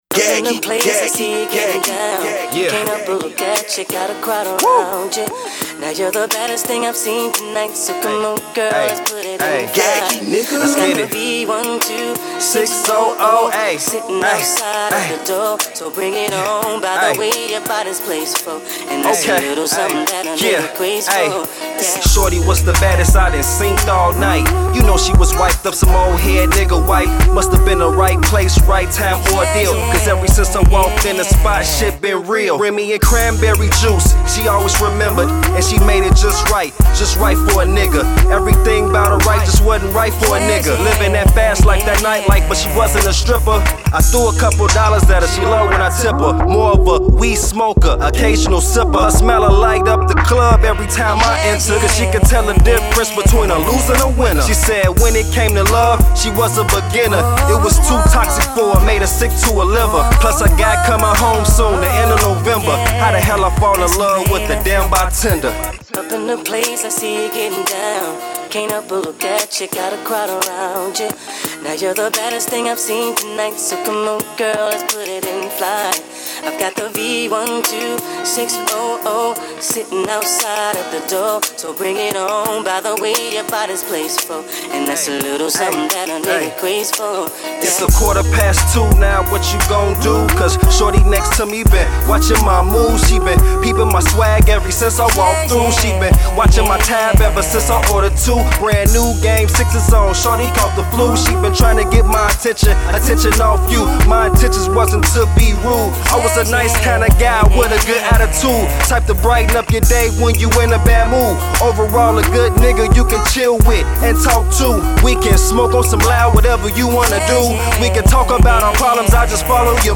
Hiphop
The joint is smoothed out and mellow just for the ladies